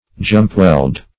Jumpweld \Jump"weld`\